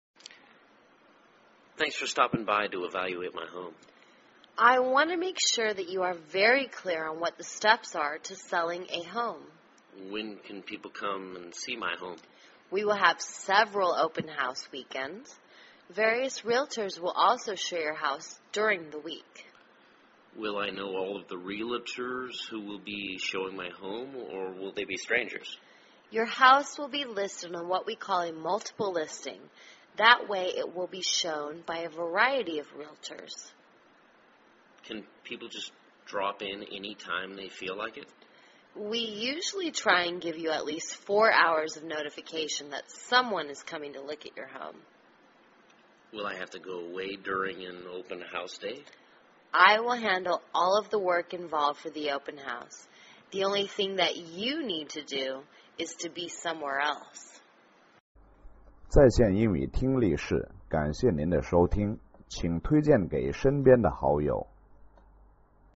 卖房英语对话-Marketing Your House(3) 听力文件下载—在线英语听力室